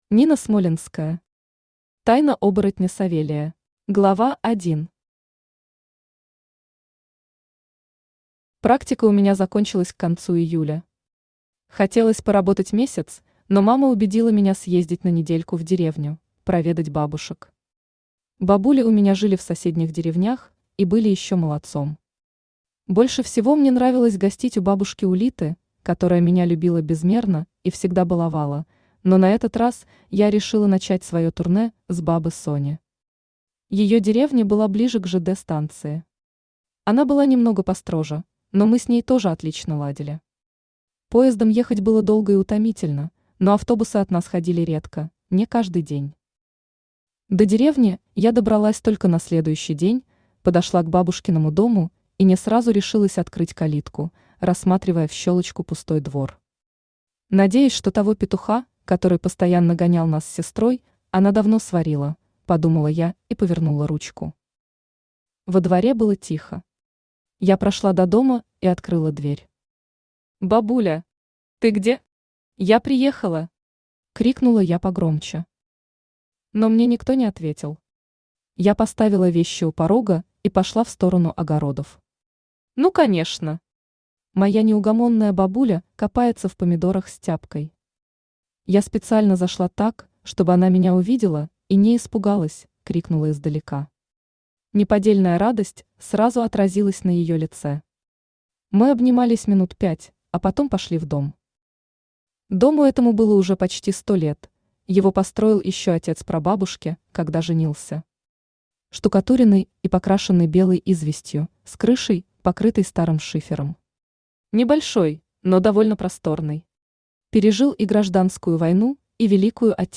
Aудиокнига Тайна оборотня Савелия Автор Нина Смолянская Читает аудиокнигу Авточтец ЛитРес.